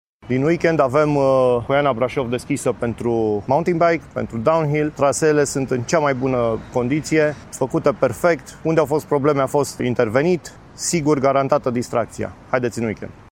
Unul dintre bikerii brașoveni